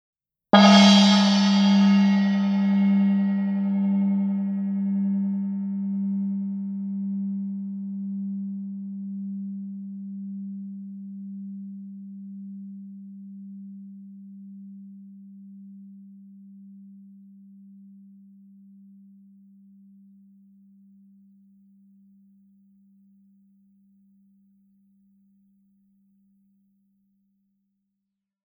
ZENN gongs are handmade to the highest quality and come in a wide range of sizes, from 10 to 38, to suit a variety of uses and situations.